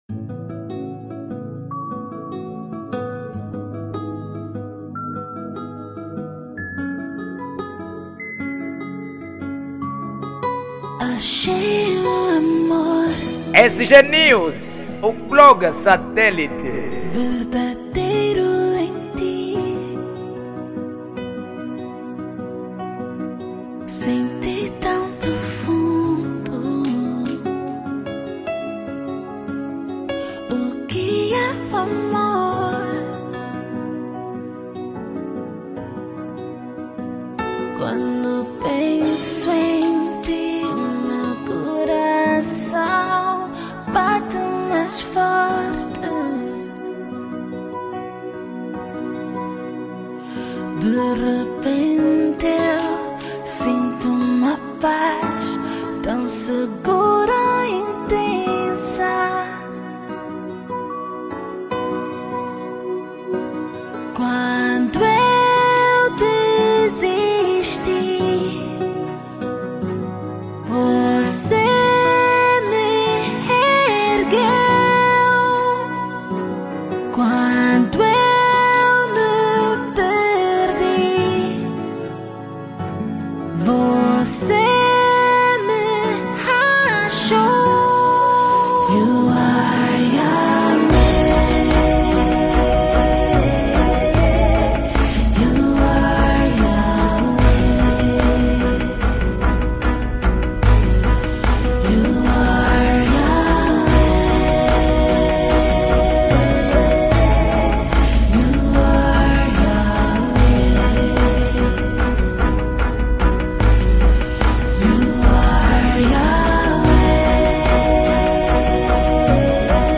Género : Soul